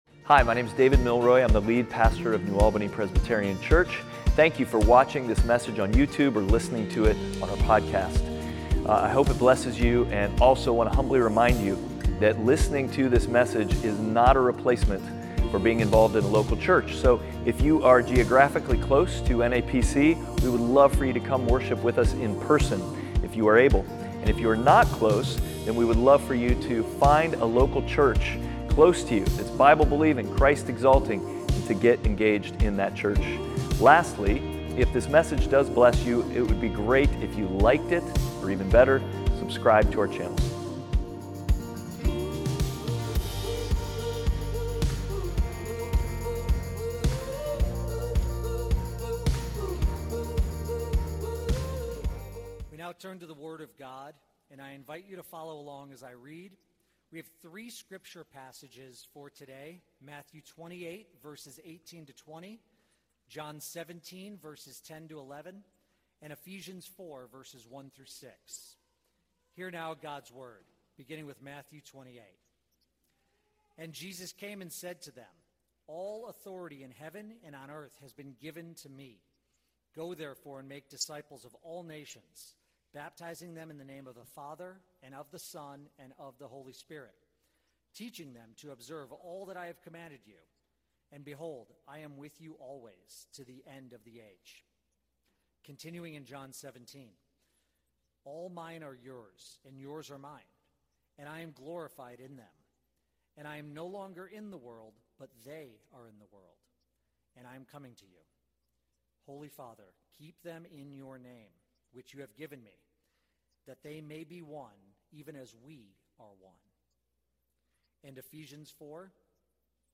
Passage: Matthew 28:18-20, John 17:10-11, Ephesians 4:1-6 Service Type: Sunday Worship